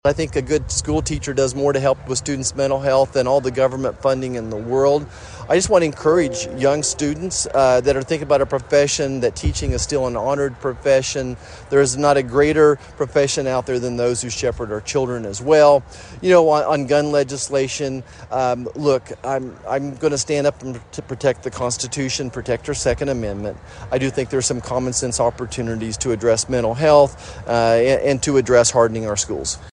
Kansas US Senator Roger Marshall, appearing at the memorial, and fellow Kansas Senator Jerry Moran set the stage in Congress for the memorial to get national designation.